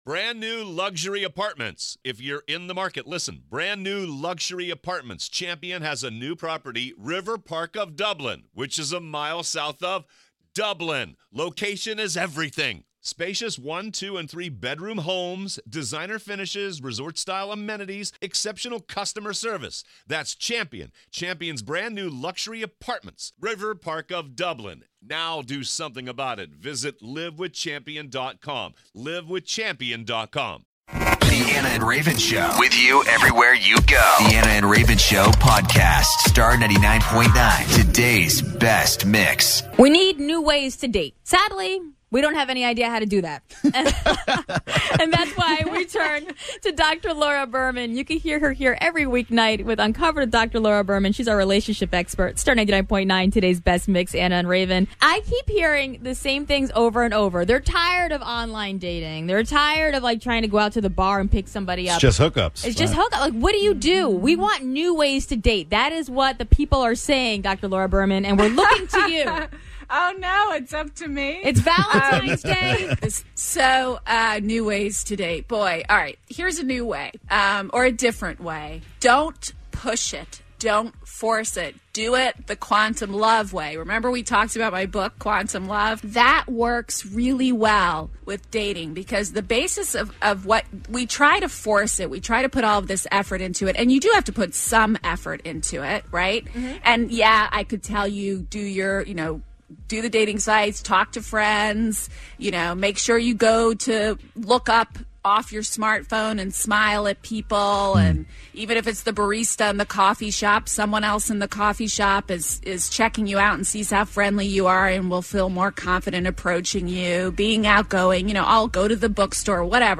2-14-17 Dr. Laura Berman came into the studio today to talk about new ways to date